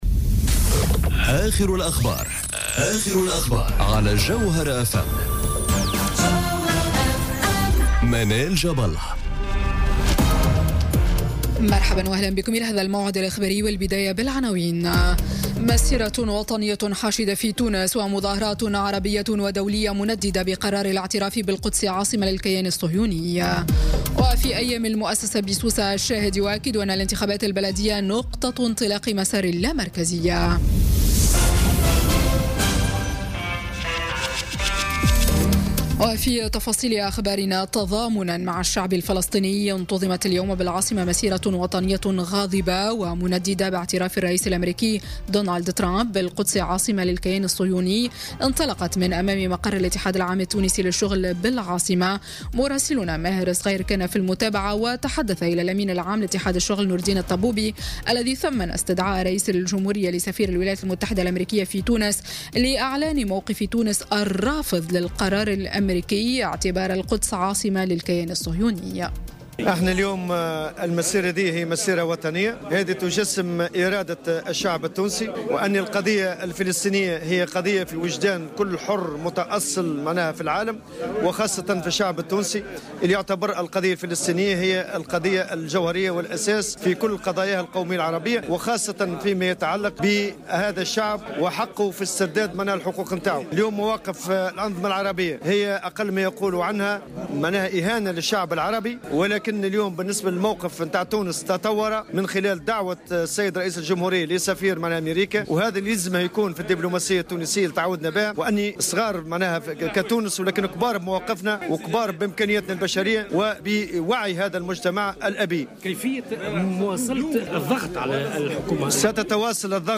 نشرة أخبار السابعة مساءً ليوم الجمعة 8 ديسمبر 2017